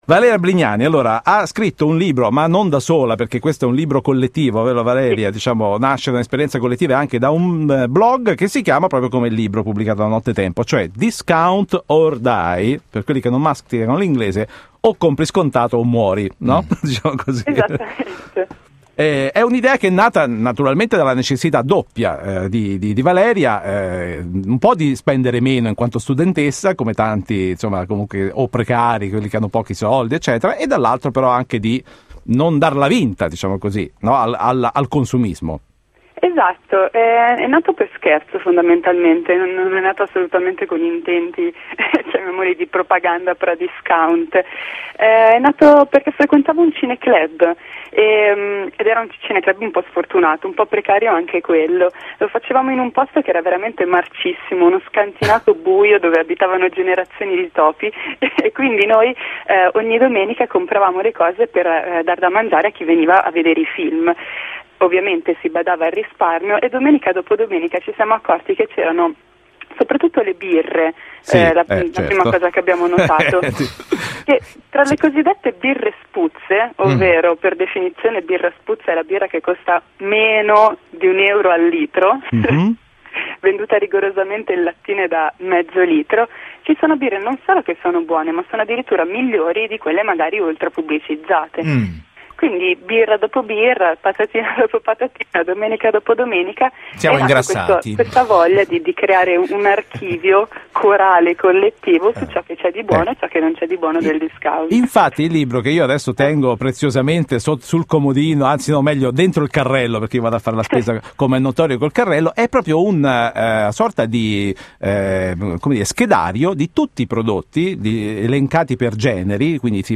D.O.D. ospite de “Il Caffé” di Radio Capital